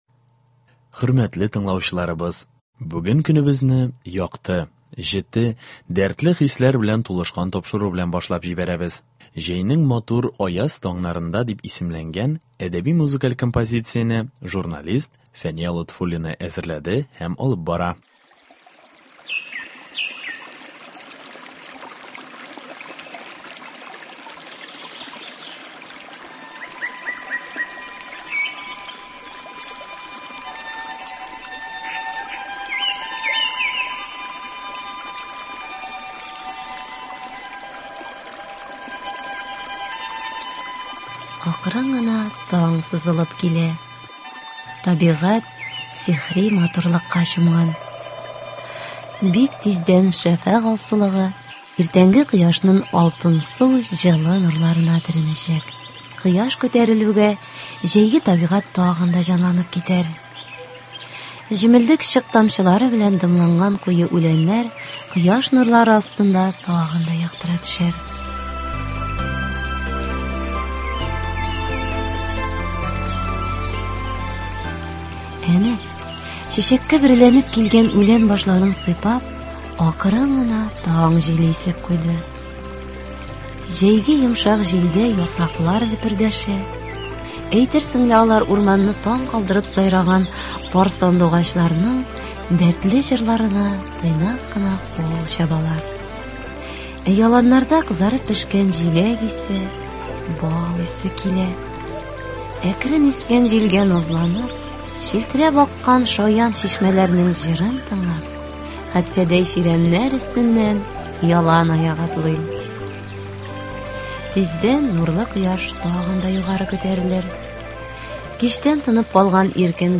Әдәби-музыкаль композиция. 13 июнь.